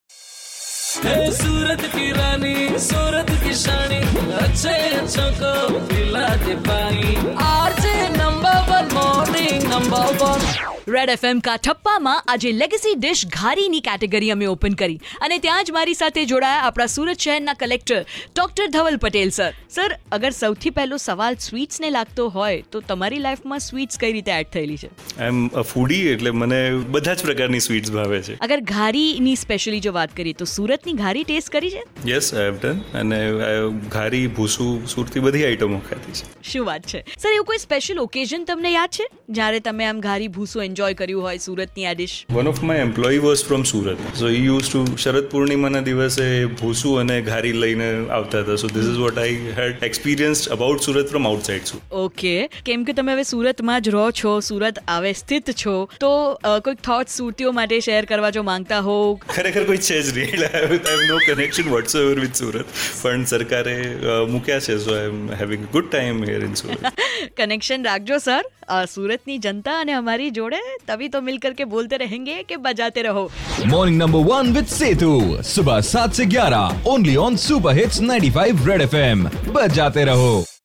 COLLECTOR SPEAKING ON HIS GHARI EXPERIENCES